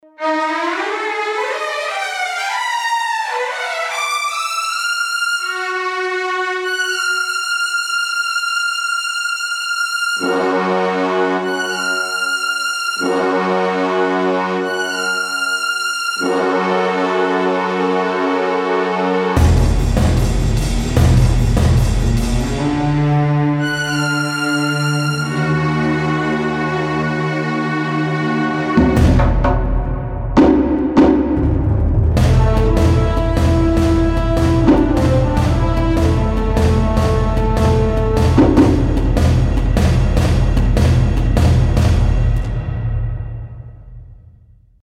Incidental Music Samples